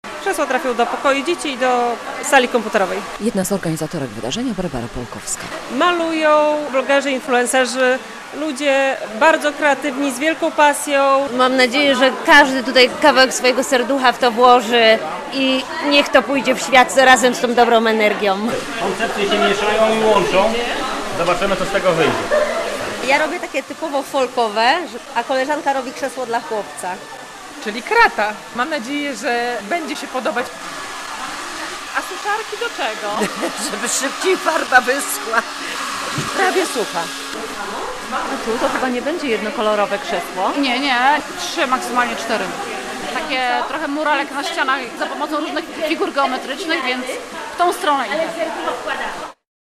Krzesła dla podopiecznych Pogotowia Opiekuńczego "Opoka" w Wasilkowie - relacja